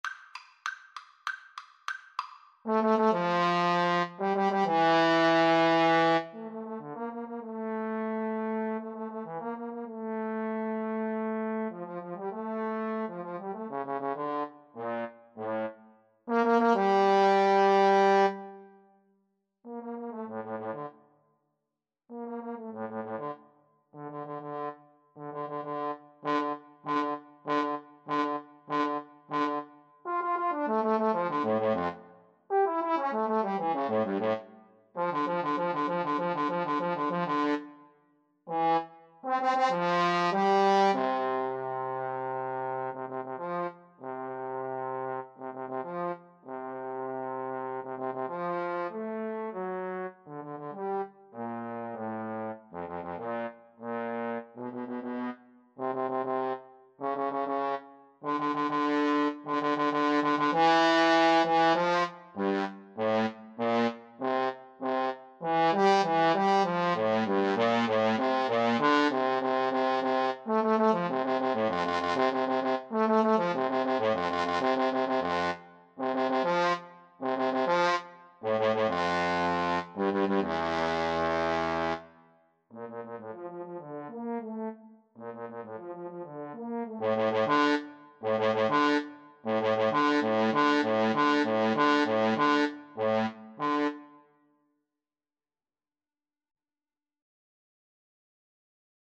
Allegro con brio (=108) =98 (View more music marked Allegro)
2/4 (View more 2/4 Music)
Classical (View more Classical Trombone Duet Music)